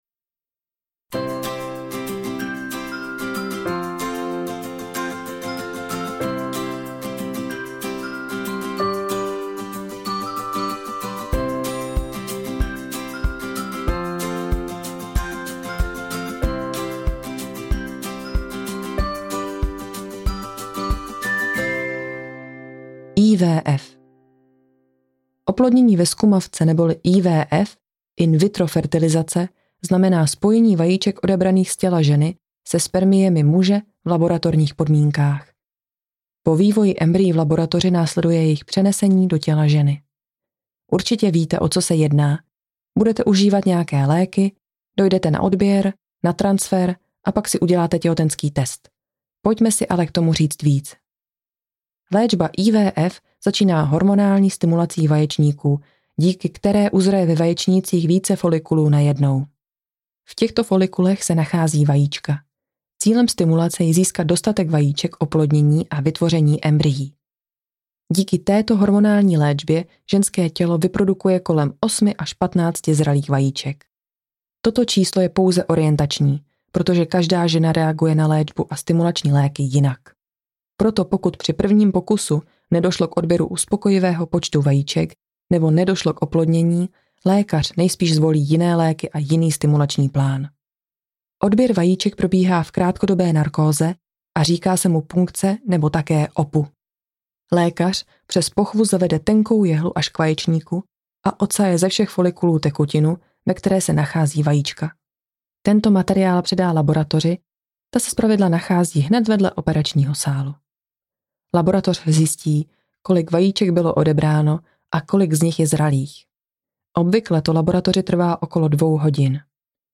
Jak na IVF audiokniha
Ukázka z knihy